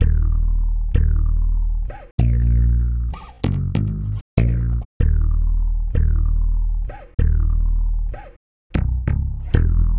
Night Rider - Stick Bass.wav